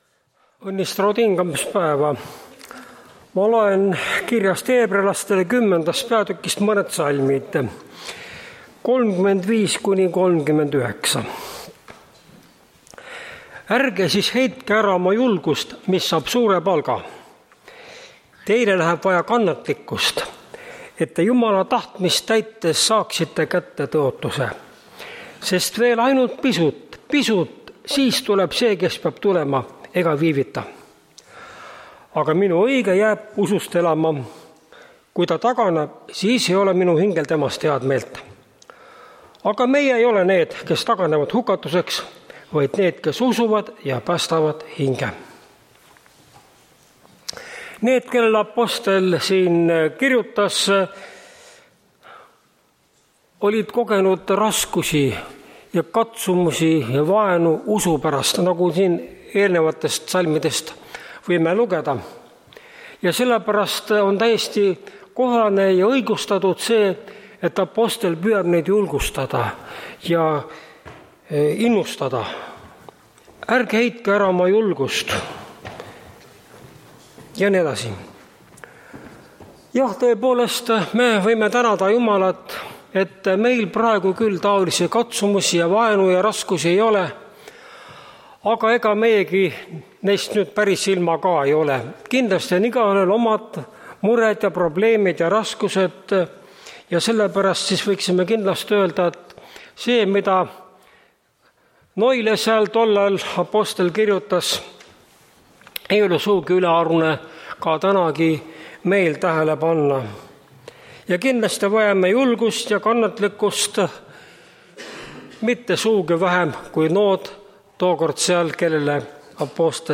Tartu adventkoguduse 03.12.2022 hommikuse teenistuse jutluse helisalvestis